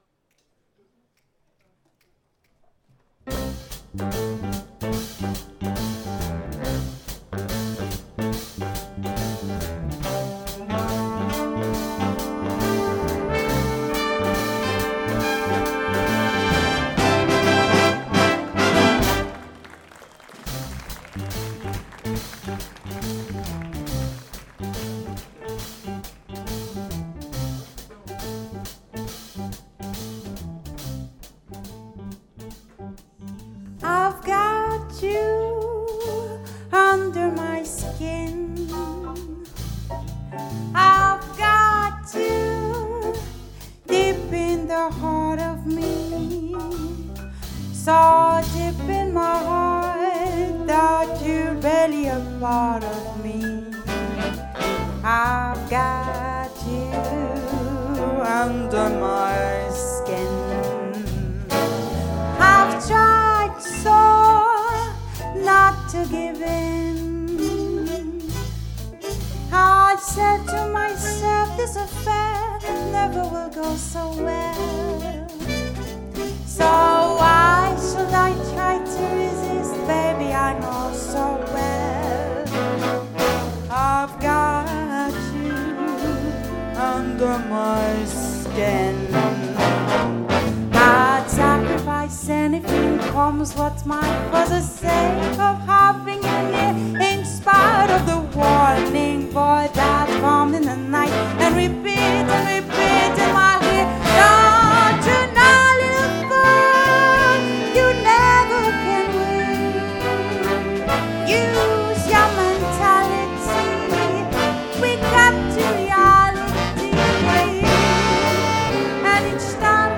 sur la scène du festival en 2024
Saxophone
Trombone
Trompette
Chant